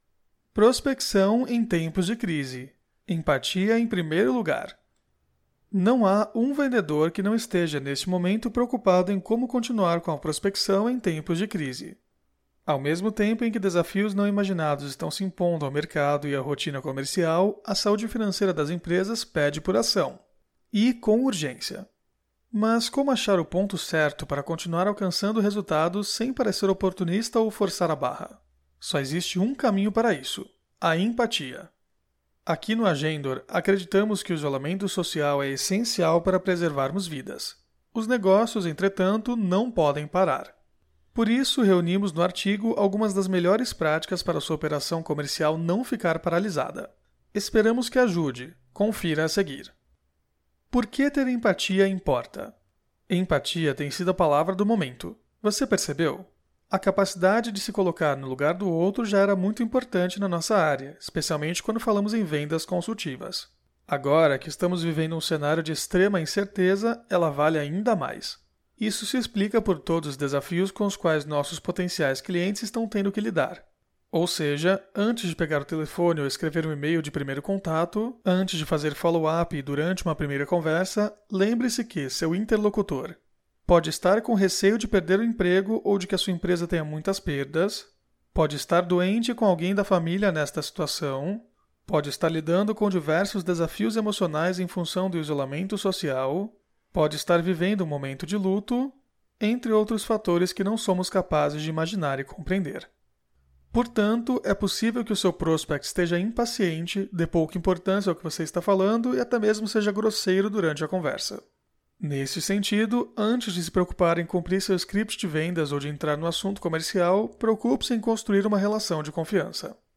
Aula 05
audio-aula-prospeccao-em-tempos-de-crise-empatia-em-primeiro-lugar.mp3